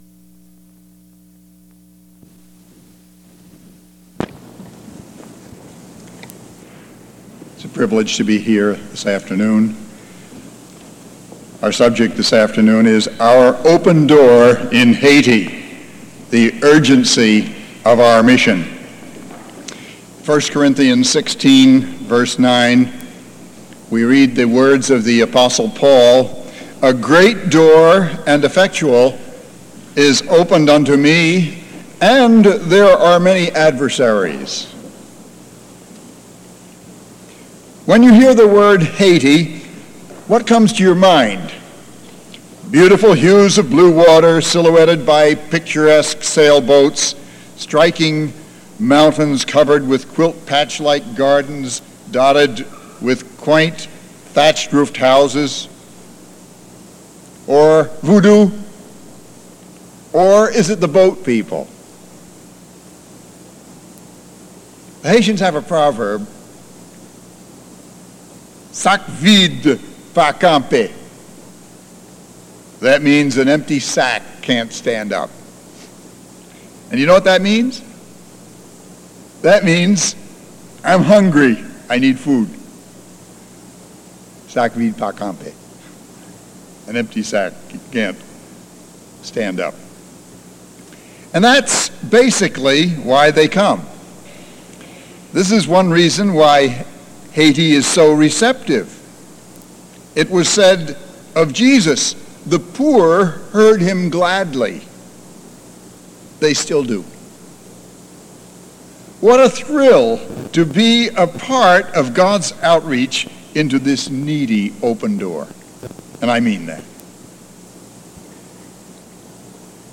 Houghton Bible Conference 1982 - Missionary Rally